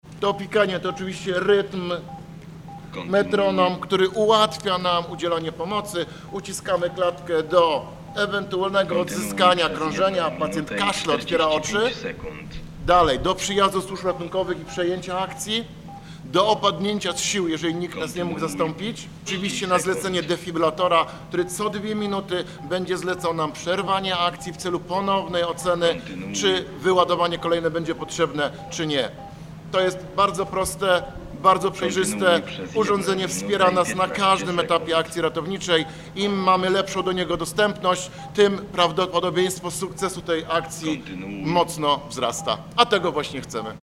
Podczas otwierania skrzynki pojawia się sygnał, a sam defibrylator posiada słowną instrukcję.